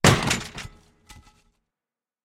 ladder-break.ogg.mp3